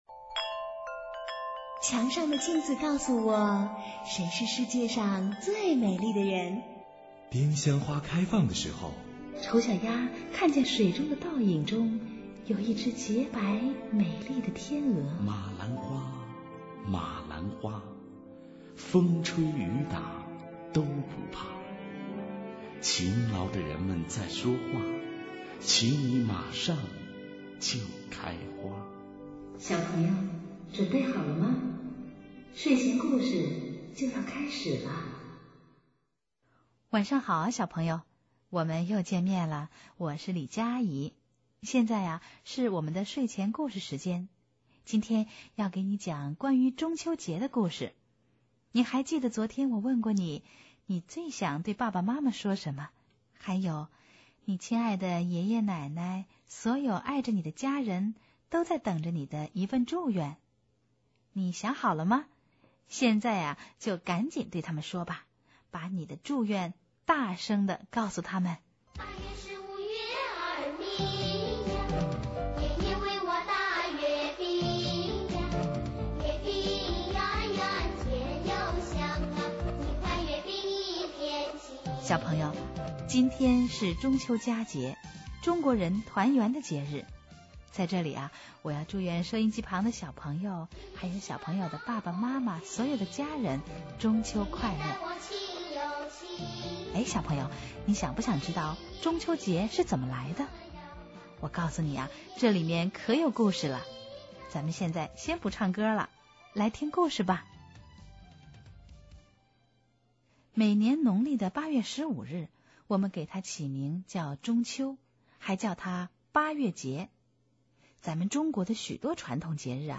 睡前故事2007年9月25日